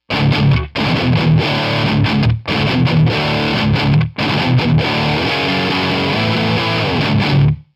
And for a bit of history, an early 60’s Ace Tone (predecessor to Roland) 2×12 closed back cabinet loaded with an original Ace Tone speaker and a Celestion G12K-85 speaker.
All examples recorded using a Jet City JCA20H 20watt head loaded with Groove Tube 12AX7’s and JJ Electronics EL84’s. For the “metal” examples a T Rex Crunchy Frog overdrive pedal and Decimator noise gate are added to the signal chain before the amp.
The guitar used is a 1978 Gibson Les Paul with a Burstbucker Pro pickup in bridge position and TonePros bridge modifications.
All examples were recorded with Audix I5 mics placed directly on the speaker grill at a 45 degree angle and run straight into a Roland Octa-Capture with no signal processing and no processing within SONAR X1 Producer Expanded.
Metal–Ace Tone 2×12 Celestion
Metal-Ace-Tone-2x12-Celestion.wav